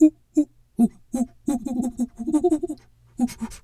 monkey_2_chatter_06.wav